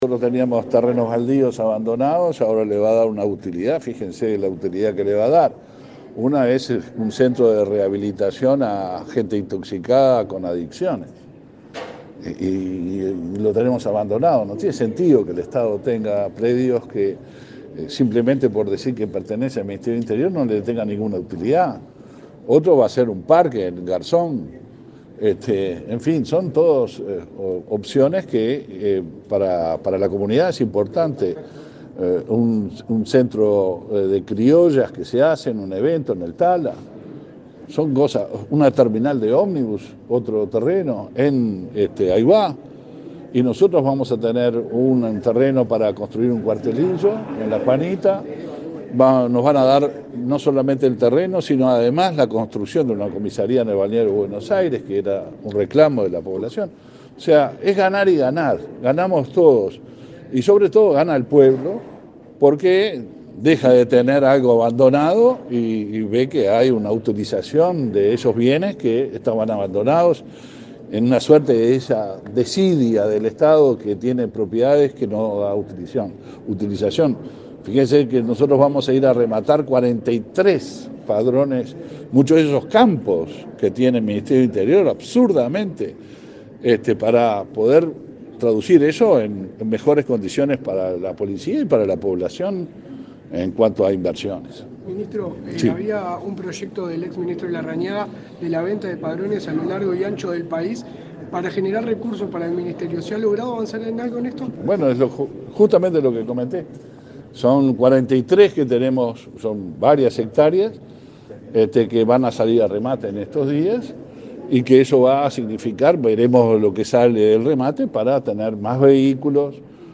Declaraciones a la prensa del ministro del Interior, Luis Alberto Heber
Luego, Heber dialogó con la prensa.